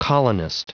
Prononciation du mot colonist en anglais (fichier audio)
Prononciation du mot : colonist